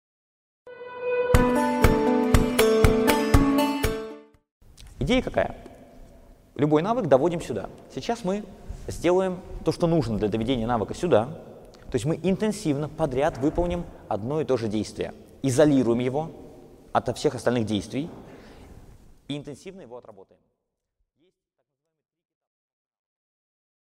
Аудиокнига Техника запоминания. Постановка навыков при обучении языкам | Библиотека аудиокниг